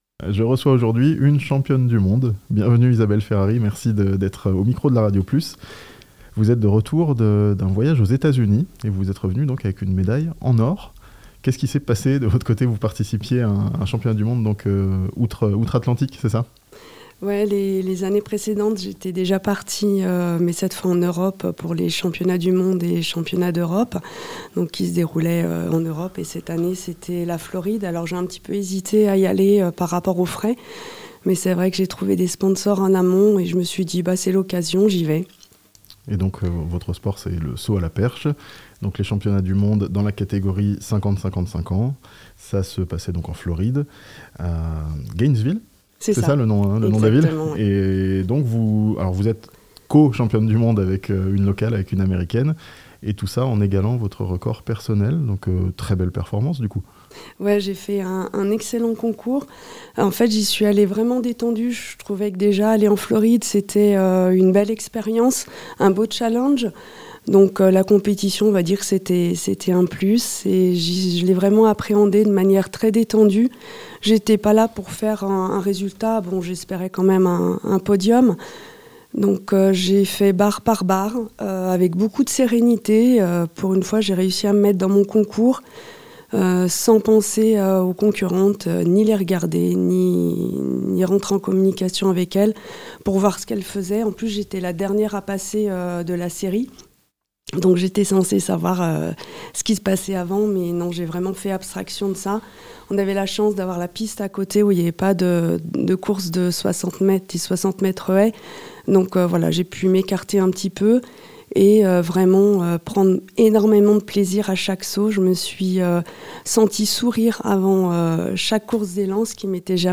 (interview)